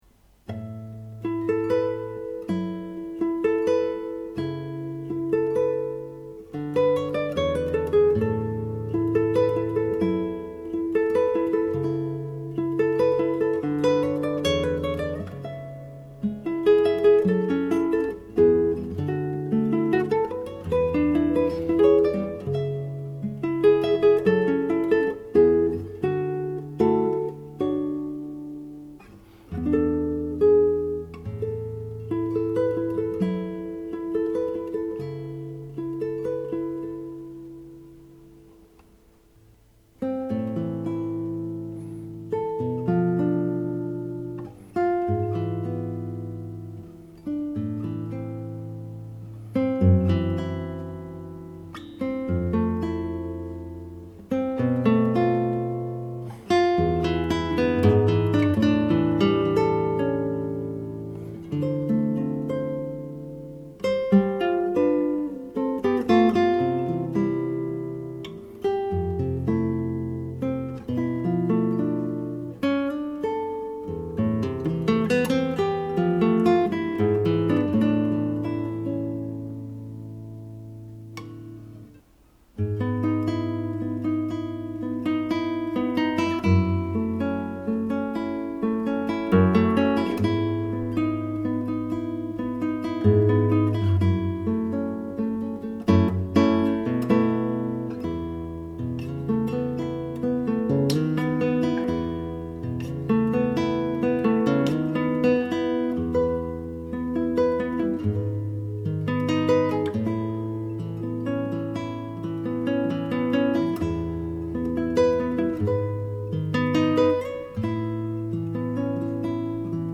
Chitarra Classica